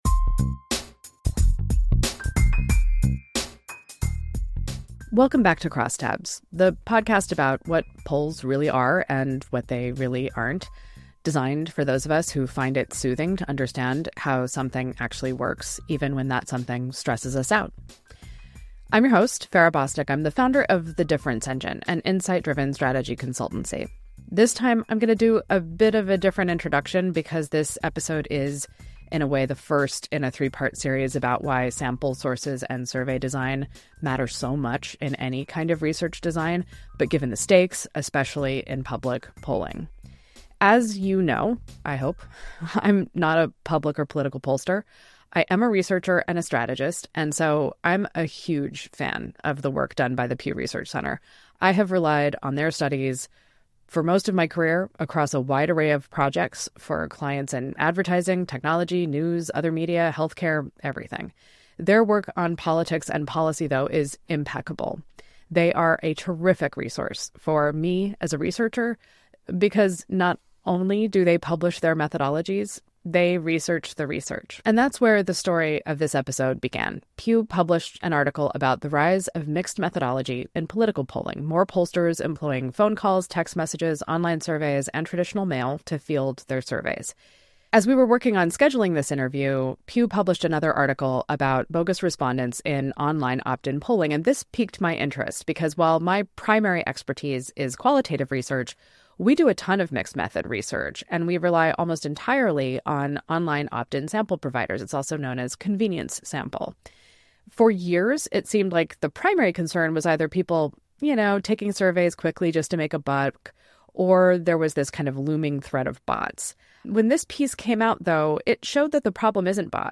The conversation offers valuable insights for anyone seeking to navigate the world of political polling (or other survey research) with a more critical eye.